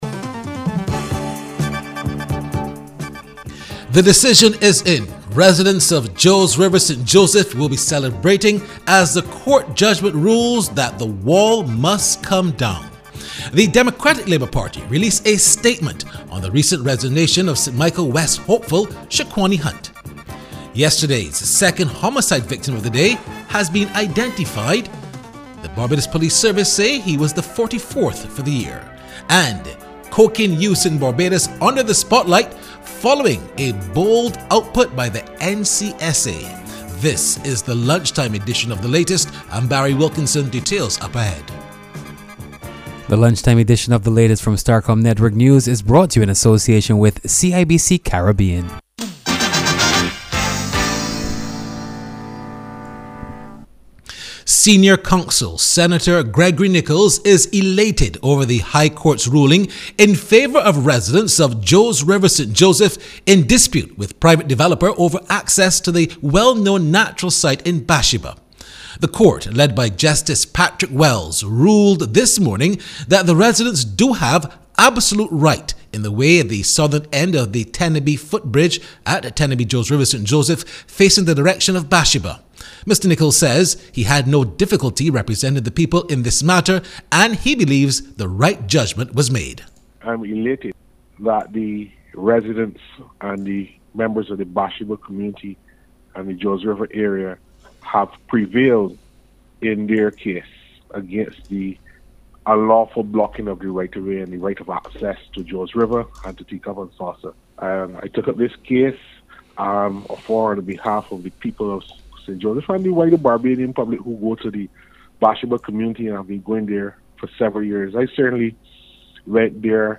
The Prime Minister then also joined the debate saying she found it shocking that the QEH was not able to have all its surgical theatres in operation and that she wants that and other matters fixed.